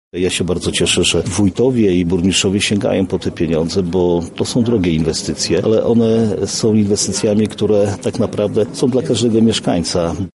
O przyznanych dofinansowaniach mówi Marszałek Województwa Lubelskiego Jarosław Stawiarski: